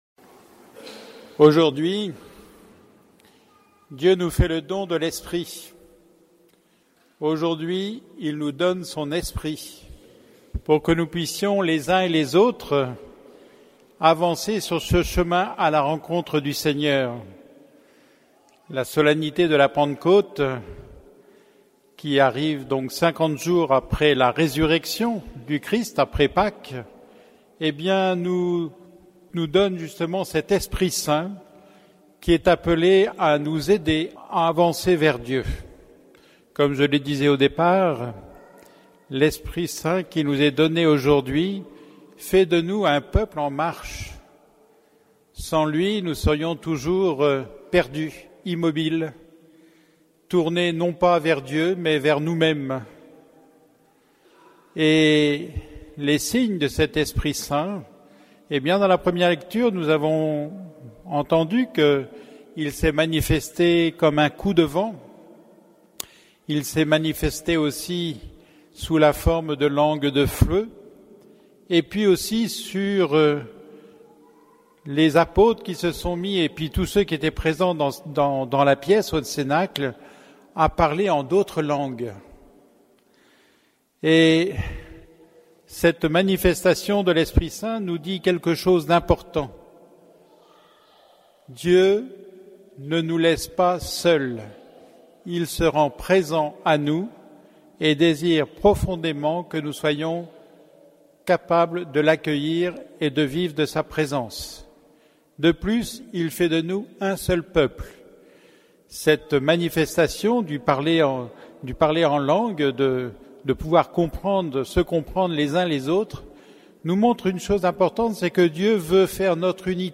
Homélie de la solennité de la Pentecôte